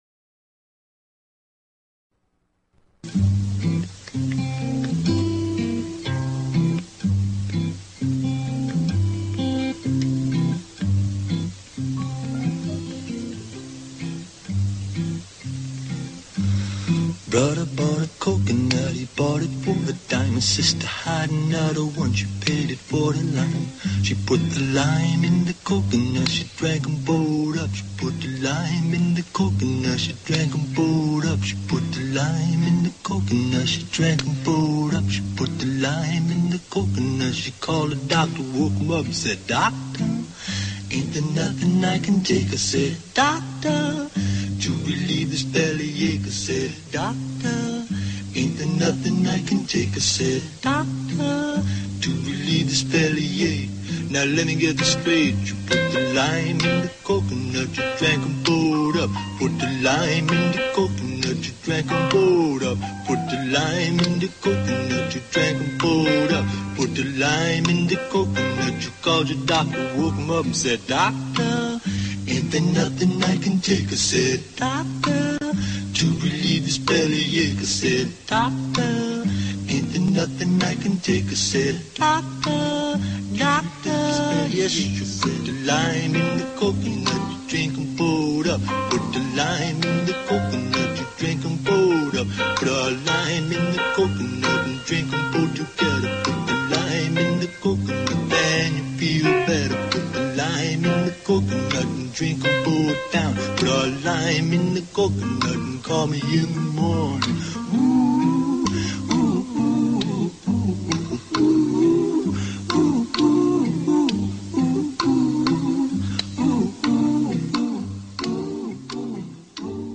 Talk Show Episode, Audio Podcast, Steady_Health_Radio and Courtesy of BBS Radio on , show guests , about , categorized as